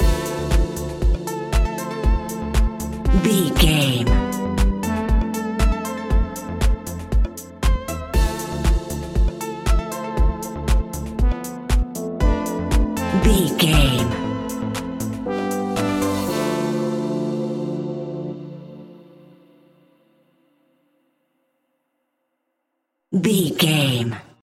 Aeolian/Minor
D
groovy
peaceful
tranquil
meditative
smooth
drum machine
synthesiser
electro house
funky house
instrumentals
synth leads
synth bass